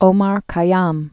(ōmär kī-yäm, -ăm) 1050?-1123?